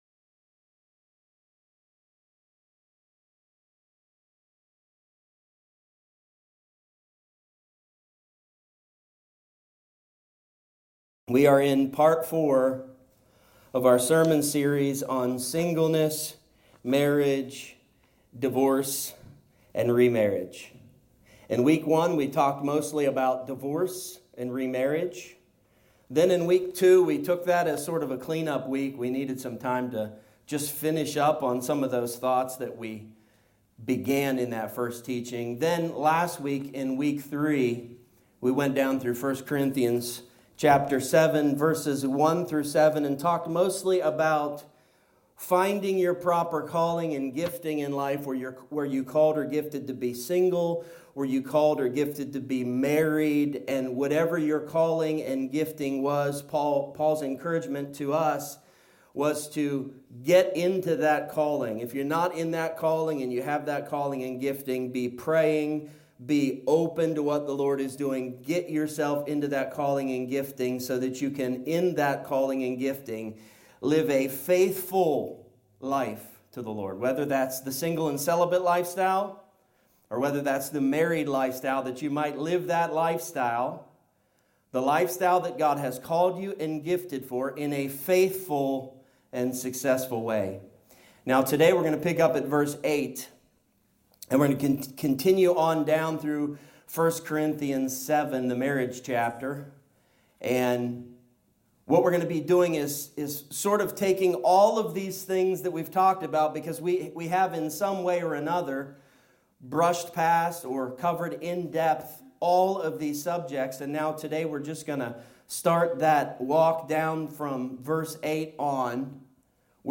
A topical teaching on the subjects of singleness, marriage, divorce, and remarriage.